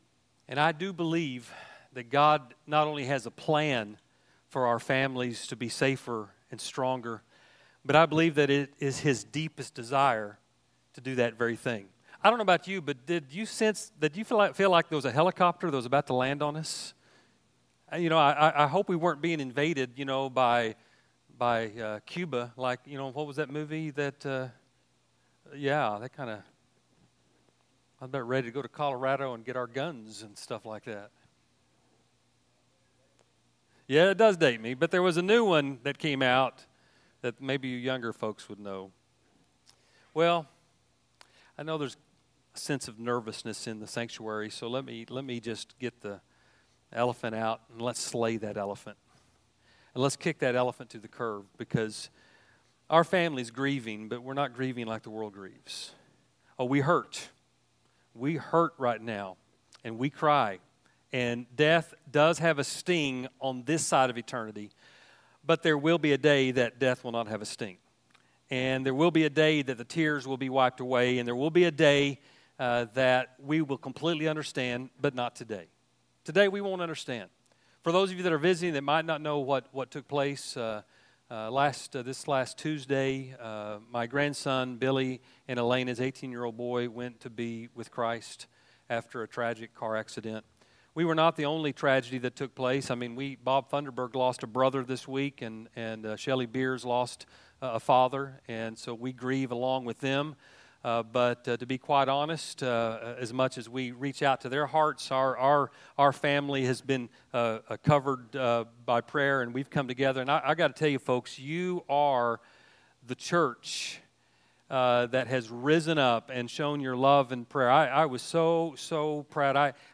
A message from the series "Last Will and Testament."